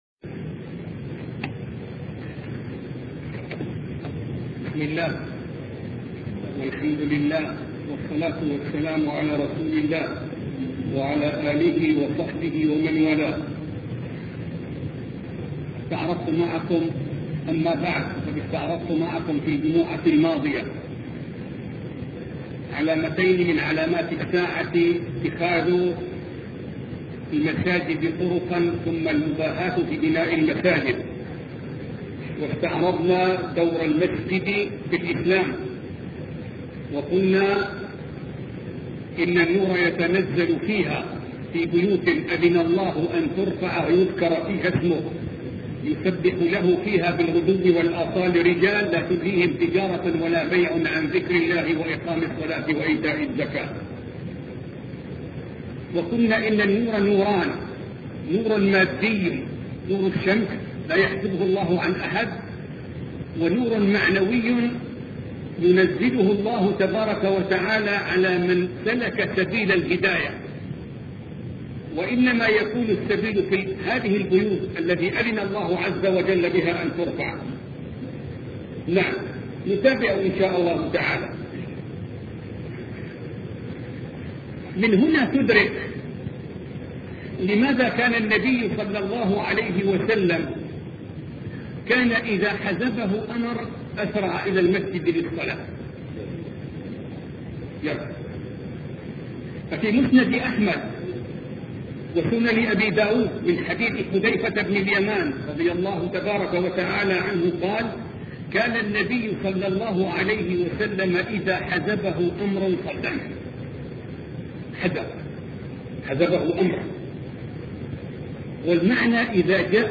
سلسلة محاضرات أشراط الساعة الوسطئ